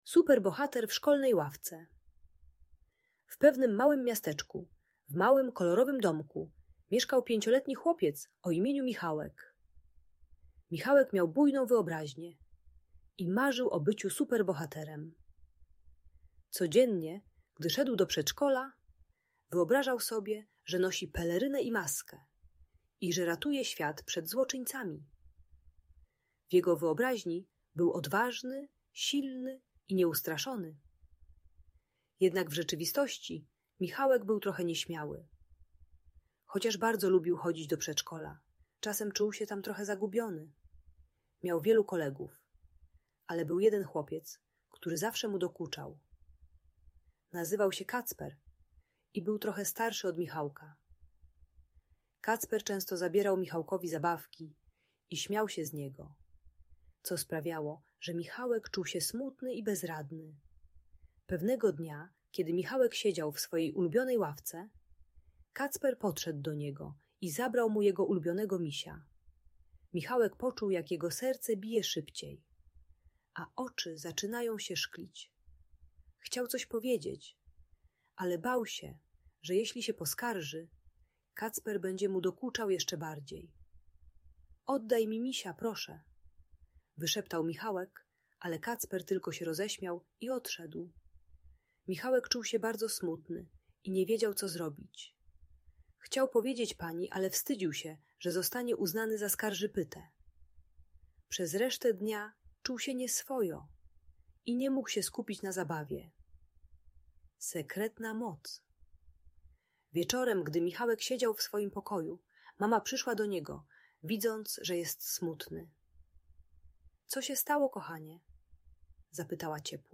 Opowieść o Superbohaterze: Historia Michałka - Audiobajka